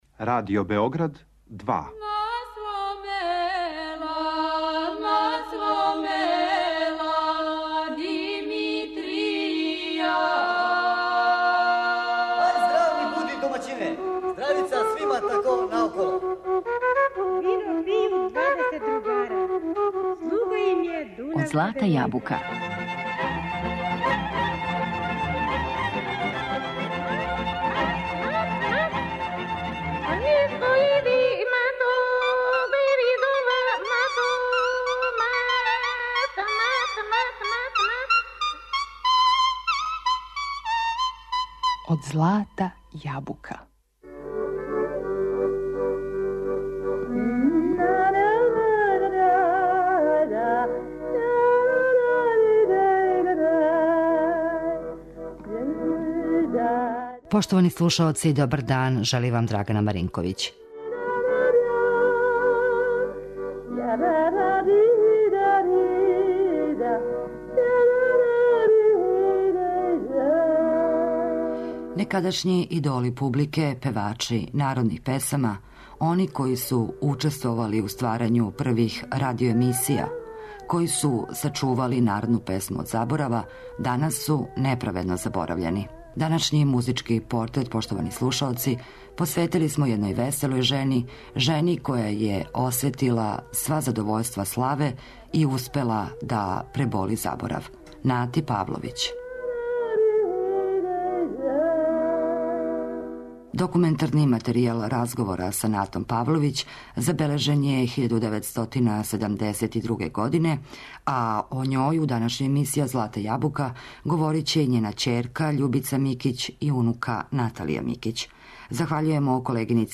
којој смо посветили данашњи музички портрет.